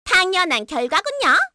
Hanus-Vox_Victory_kr.wav